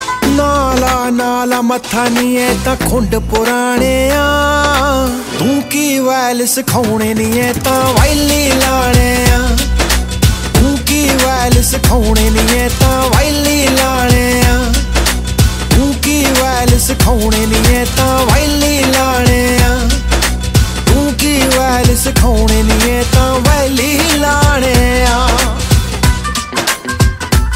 PUNJABI SONG RINGTONE for your mobile phone in mp3 format.